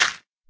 gravel4.ogg